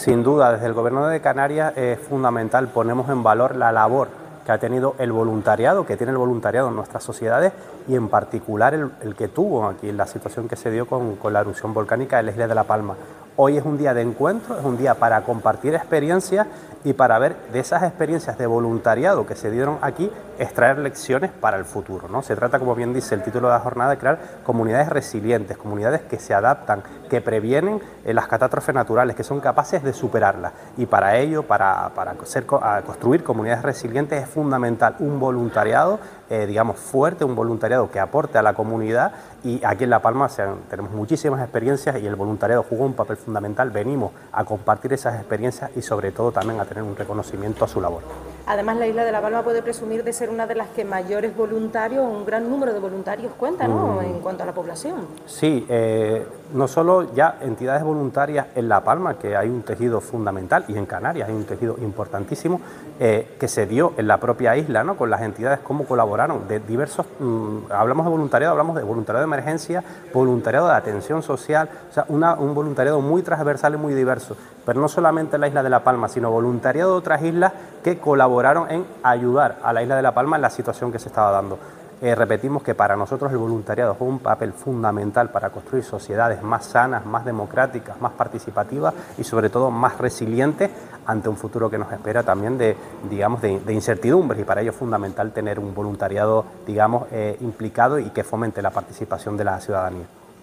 Declaraciones audio Javier Bermúdez.mp3